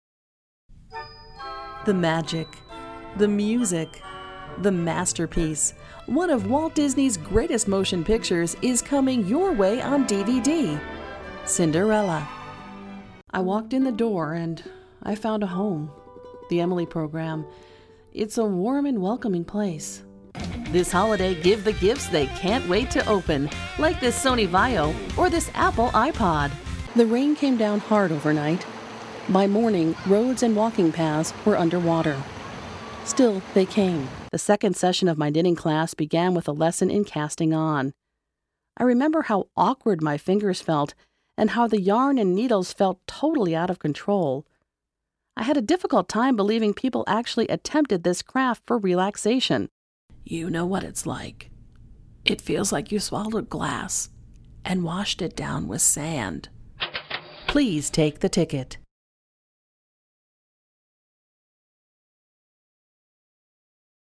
Voice Work